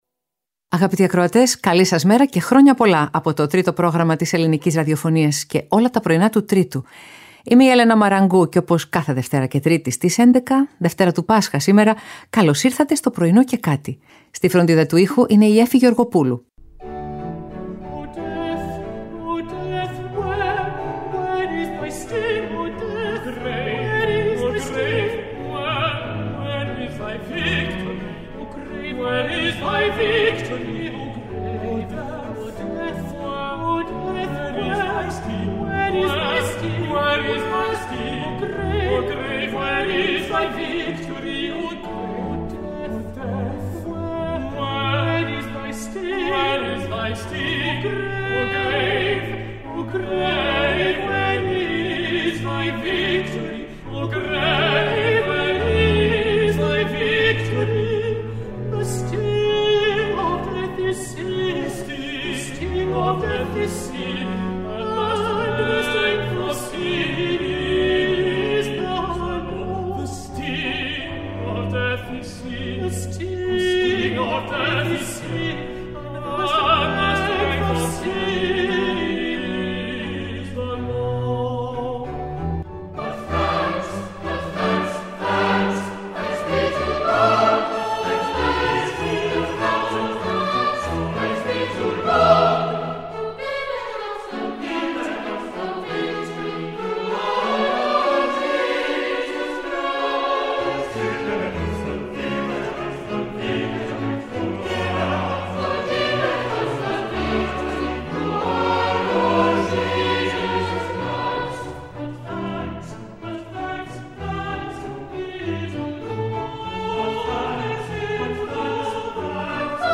Ερμηνείες από όλες τις εποχές και μερικές ανατροπές συνοδεύουν τις μικρές και μεγάλες εικόνες της ημέρας.